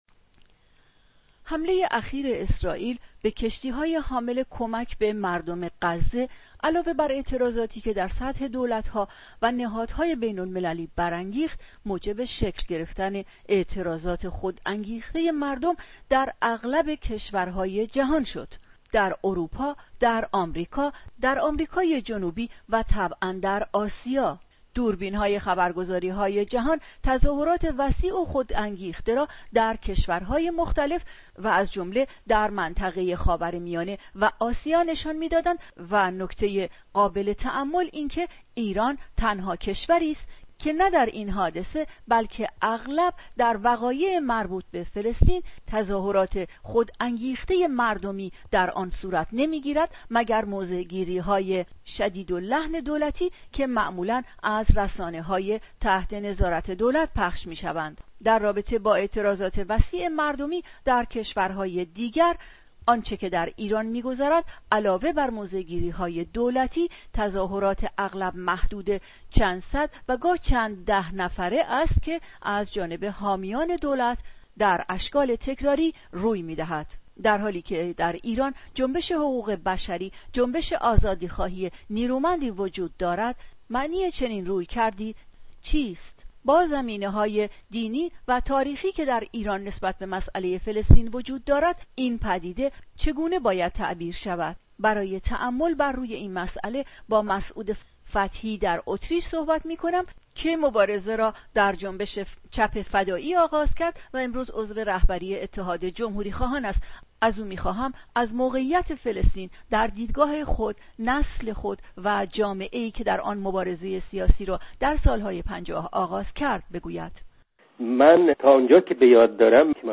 سايت های ديگر فرهنگ و ادب اقتصاد تاريخ ملی/قومی بين الملل خانه احزاب رویدادها مصاحبه گوناگون آگهی در سايت عصرنو